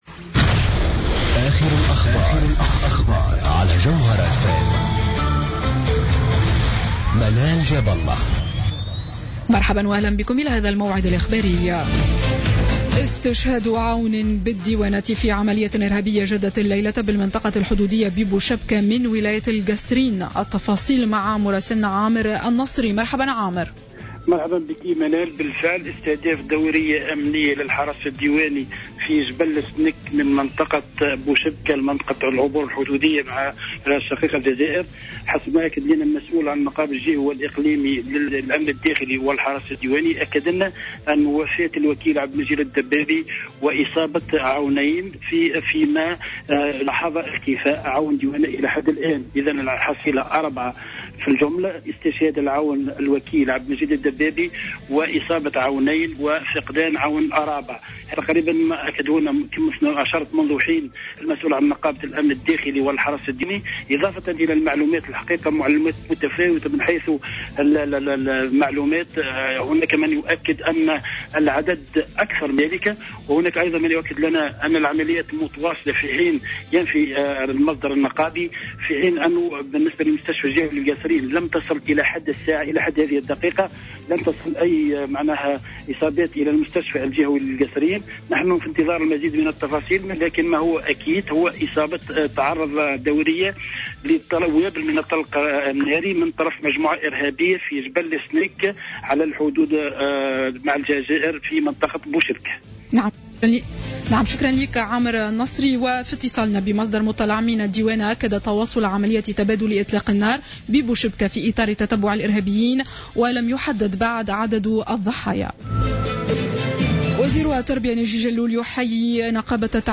نشرة أخبار منتصف الليل ليوم الاثنين 24 أوت 2015